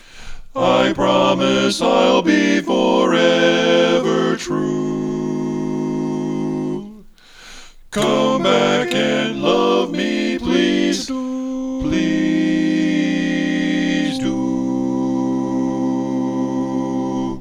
Key written in: C Major
How many parts: 4
Type: Barbershop
All Parts mix:
Learning tracks sung by